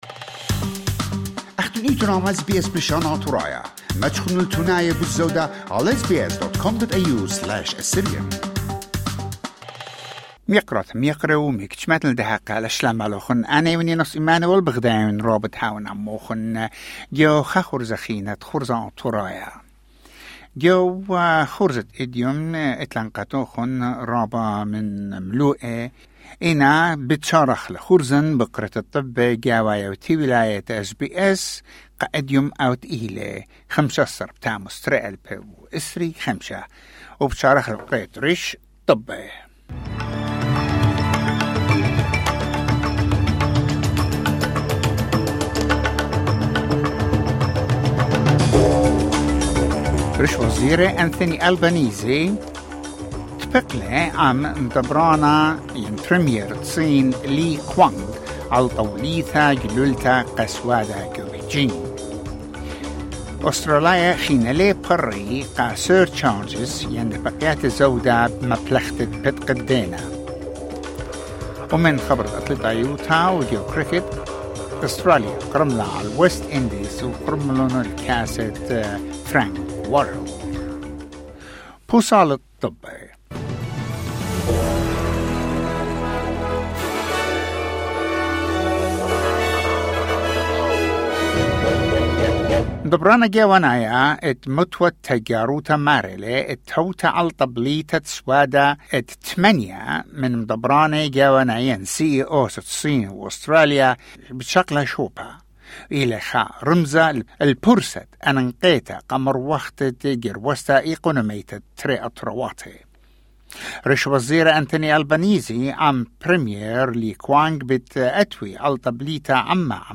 News bulletin: 15 July 2025